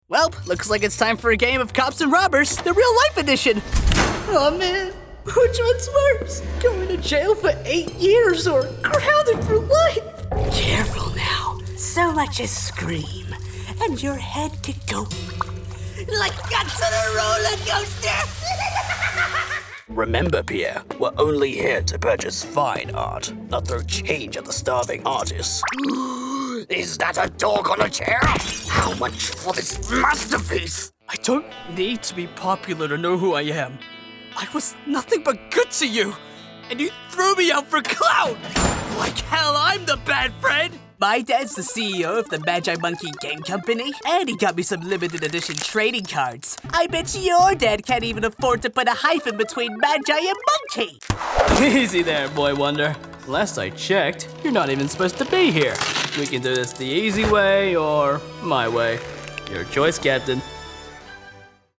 standard us | character